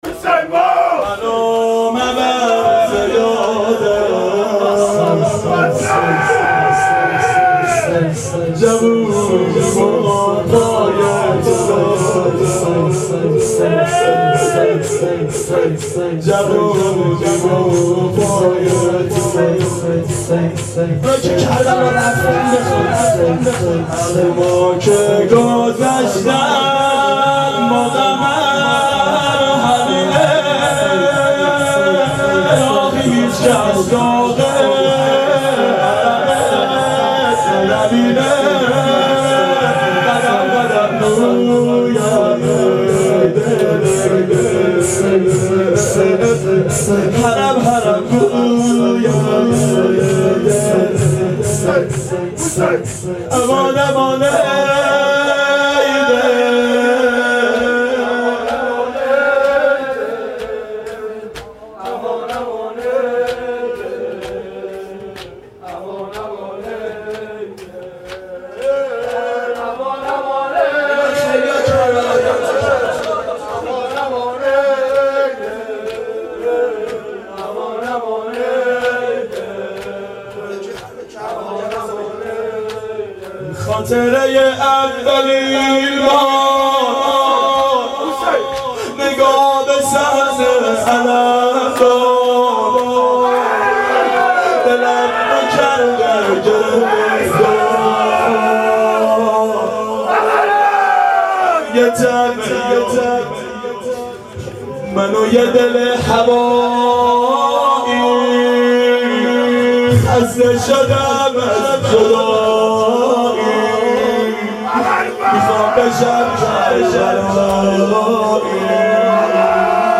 شب اربعین 92 هیأت عاشقان اباالفضل علیه السلام منارجنبان